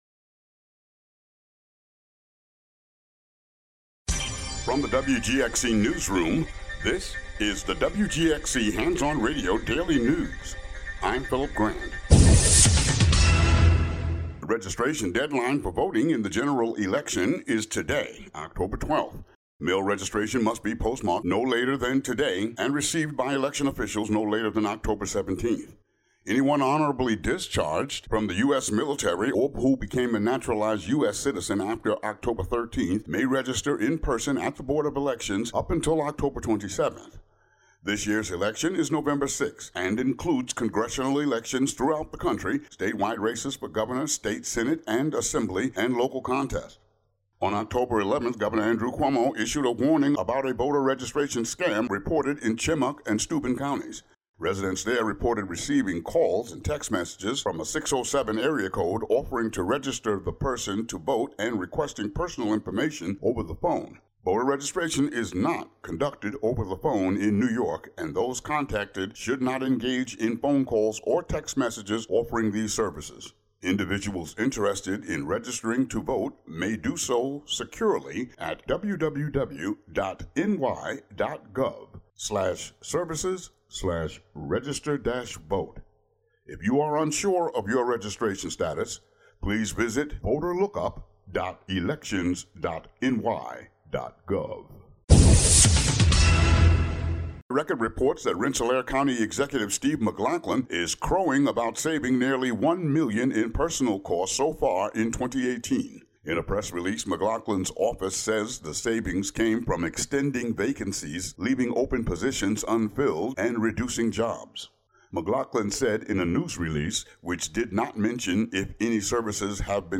"All Together Now!" is a daily news show brought t...